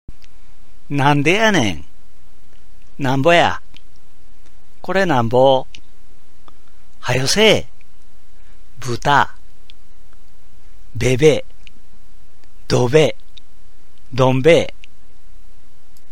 私は河内育ちの大阪弁を話しますが、聞いて分かりますか
引き続いて、大阪弁の独特の言葉をピックアップして、音声を録音しています。